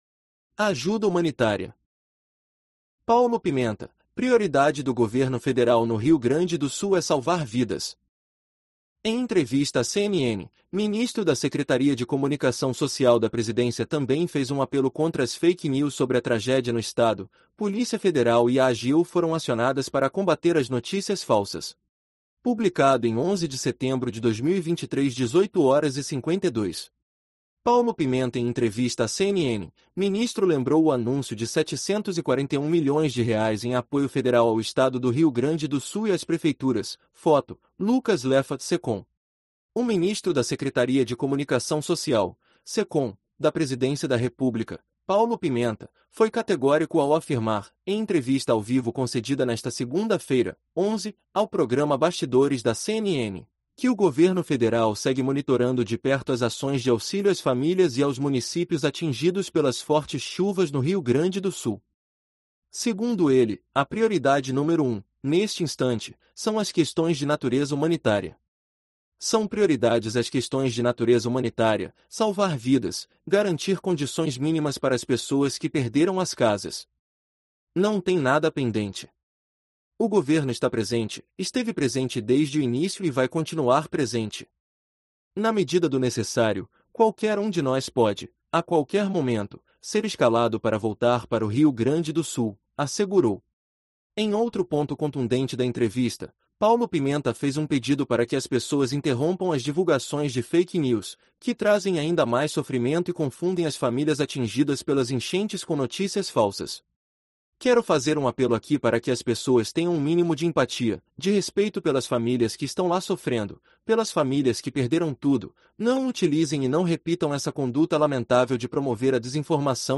Em entrevista à CNN, ministro da Secretaria de Comunicação Social da Presidência também fez um apelo contra as fake news sobre a tragédia no estado; Polícia Federal e AGU foram acionadas para combater as notícias falsas